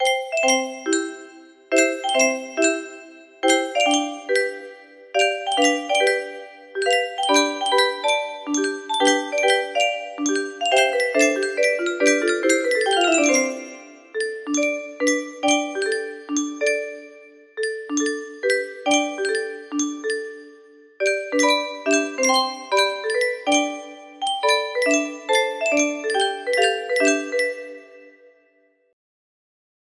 Shorter and rearranged version